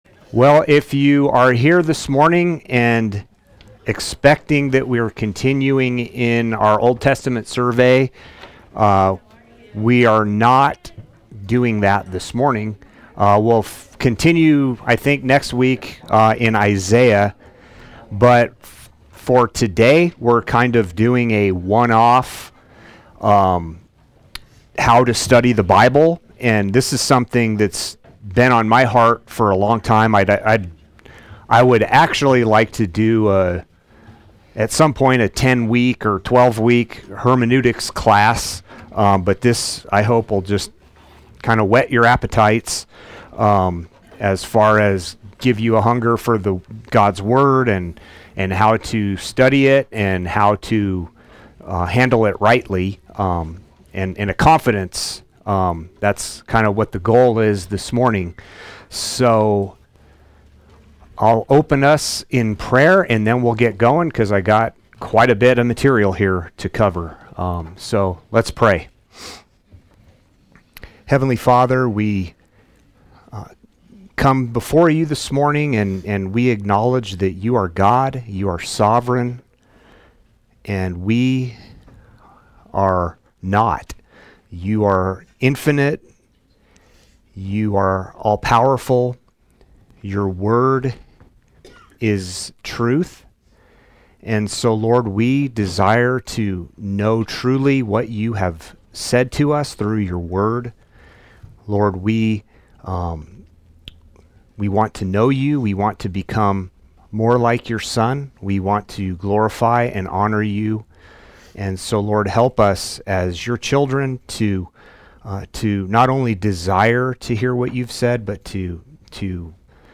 Play Sermon Get HCF Teaching Automatically.
How to Study the Bible Adult Sunday School